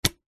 Звуки наушников